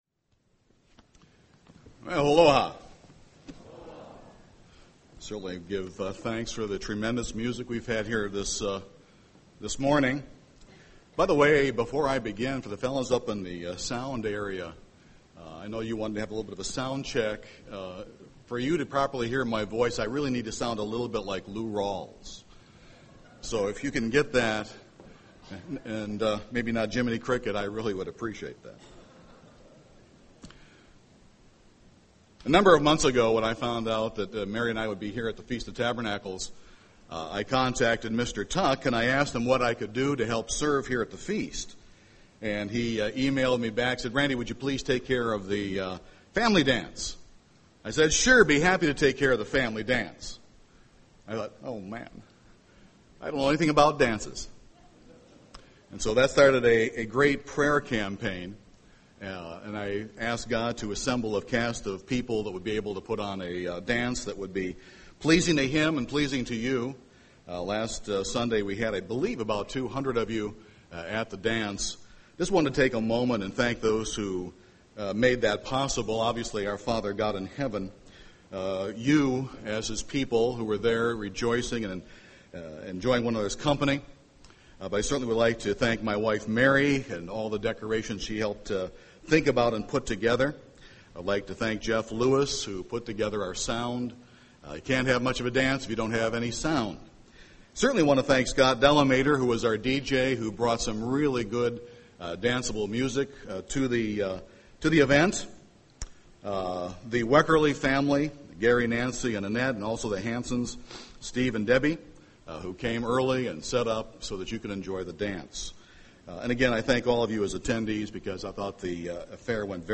This sermon was given at the Gatlinburg, Tennessee 2011 Feast site.